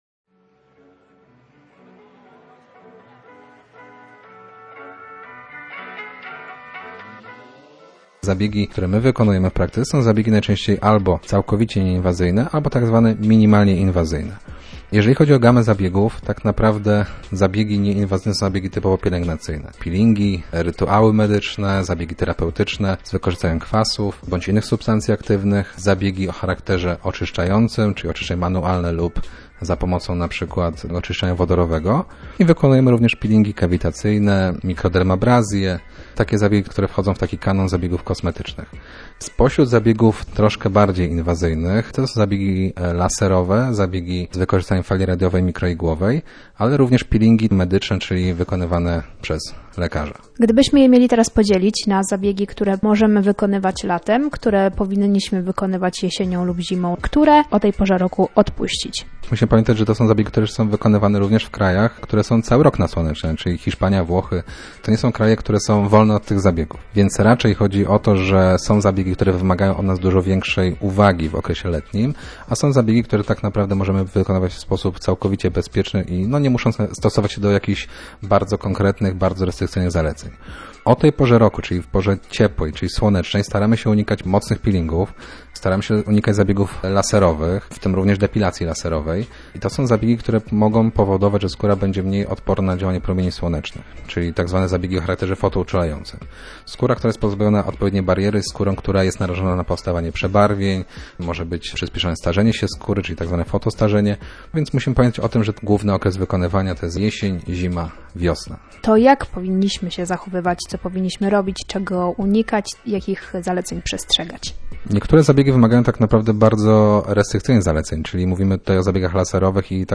archiwum audycji